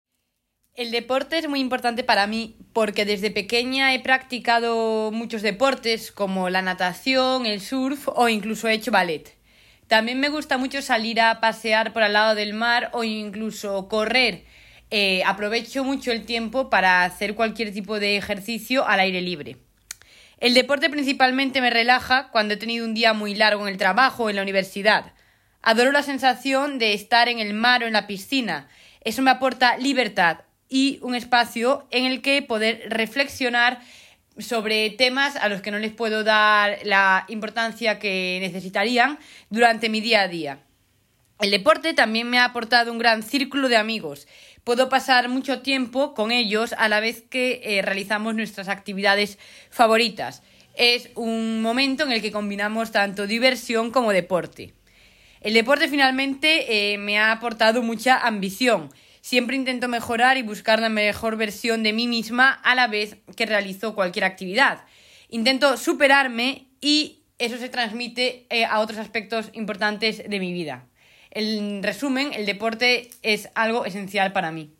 Vous trouverez dans cette nouvelle rubrique de courts enregistrements réalisés par les assistants d’espagnol nommés dans notre académie, classés par thèmes et niveau du CECRL.